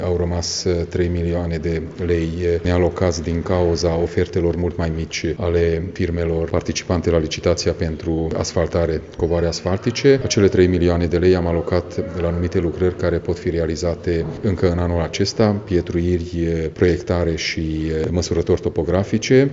Președintele Consiliului Județean Mureș, Peter Ferenc: